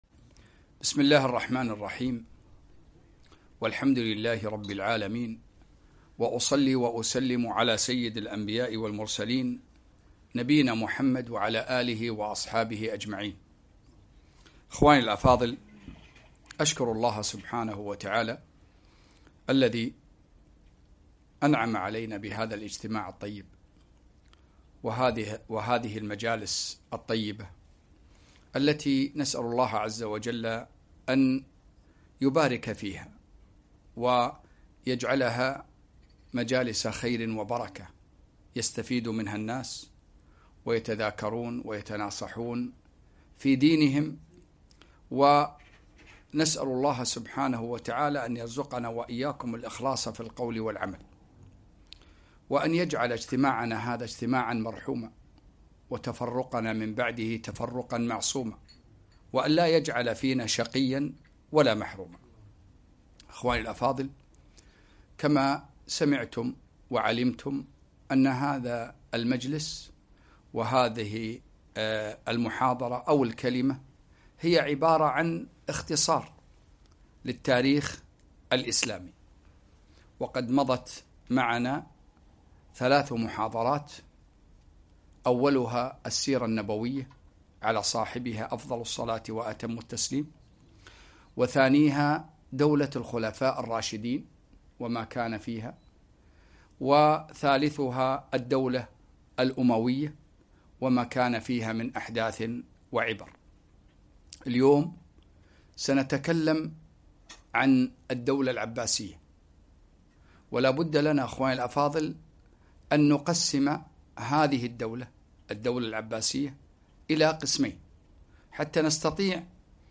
4- اختصار تاريخ المسلمين - الدولة العباسية عصر القوة في محاضرة واحدة